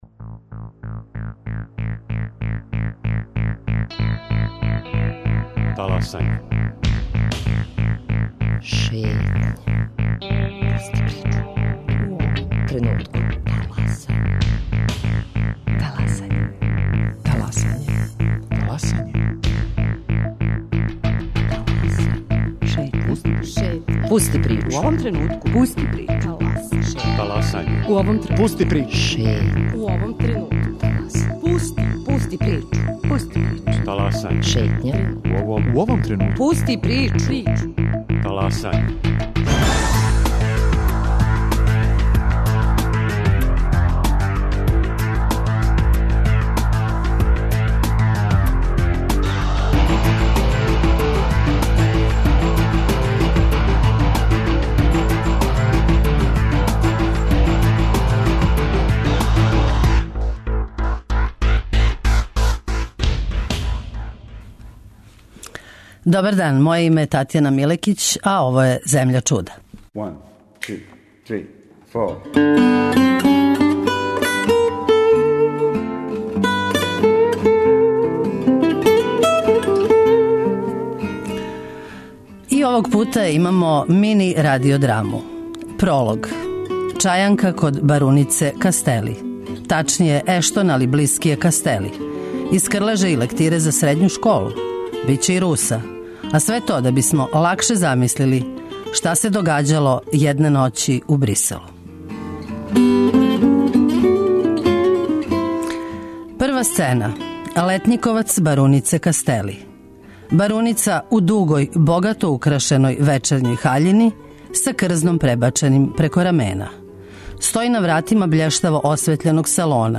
Одакле долази тај осећај - о томе у другом делу емисије, уз обиље документарних записа из протекле деценије, у којима говоре овдашњи интелектуалци.